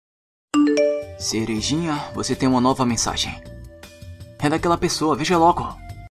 Som de notificação com a voz do Sasuke
Sons de notificação Otaku Som de notificação do Sasuke
Categoria: Toques
Agora você vai ter o Sasuke falando com você todos os dias.
som-de-notificacao-com-a-voz-do-sasuke-pt-www_tiengdong_com.mp3